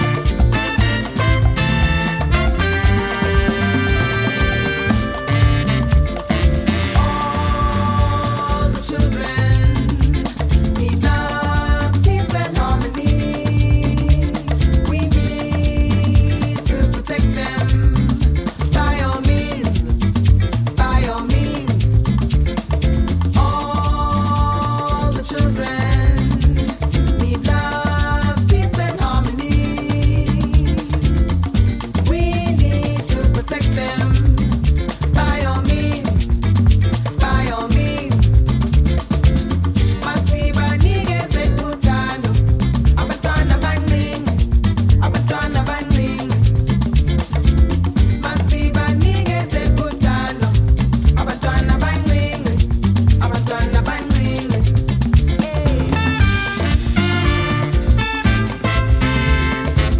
ジャンル(スタイル) AFRO BEAT / AFRICAN